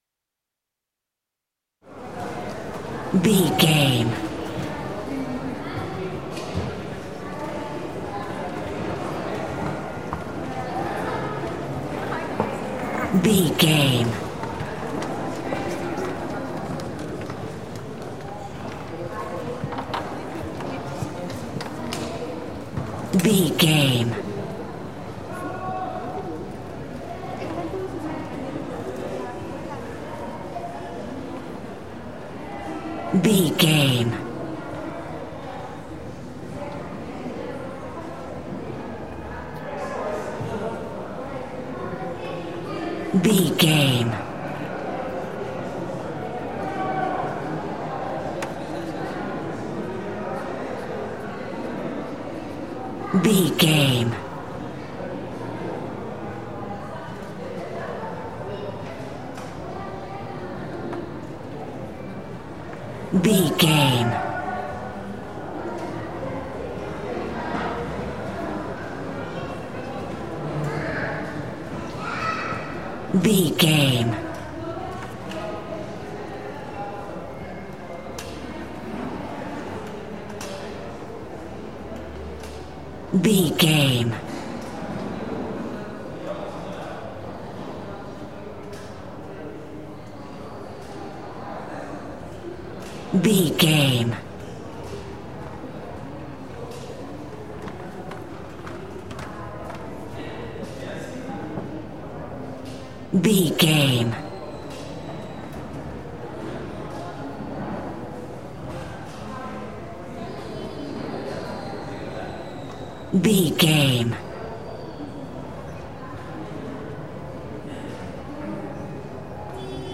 Mexico taxco church
Sound Effects
urban
ambience